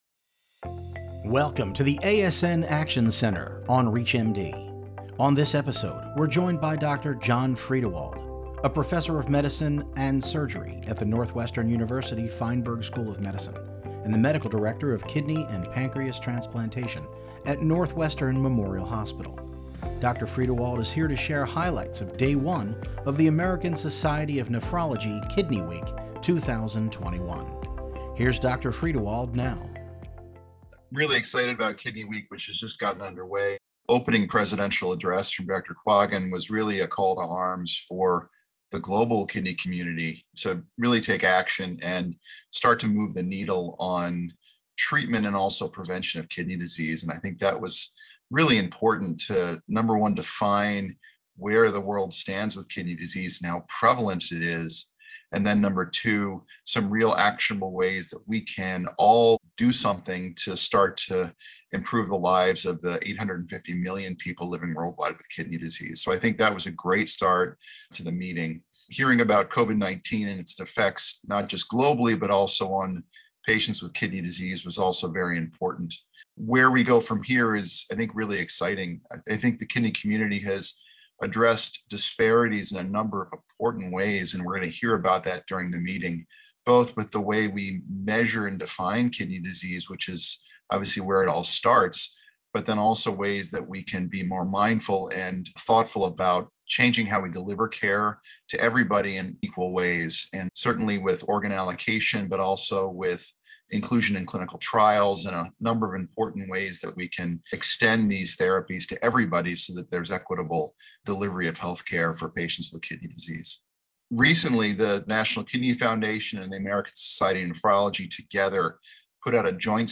Primary Care Today features conversations with clinical experts representing a wide range of medical specialties to highlight the latest trends in primary care practice, from clinical pearls to updated guidelines, health informatics, and practice management.